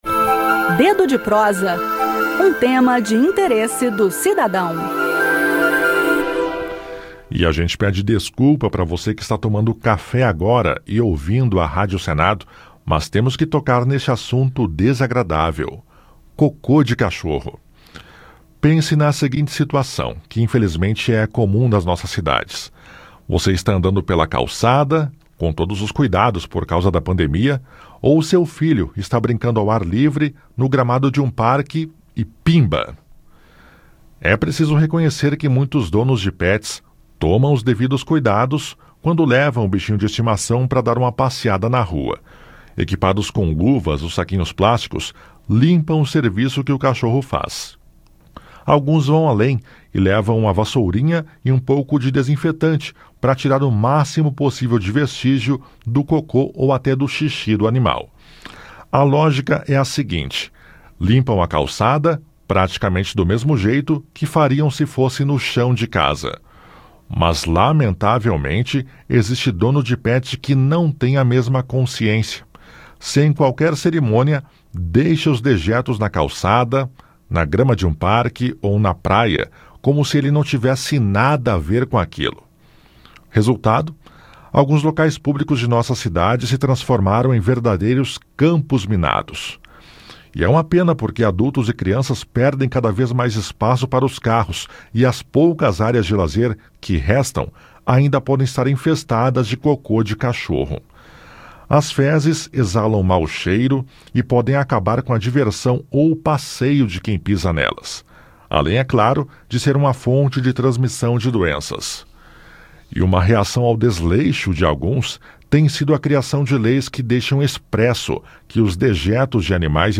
bate-papo